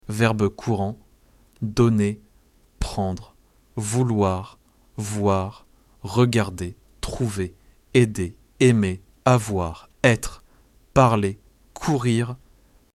Lesson 5